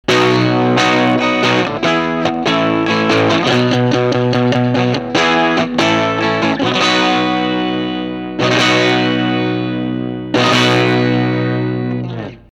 高域が若干足され、艶が増したように聴こえなくも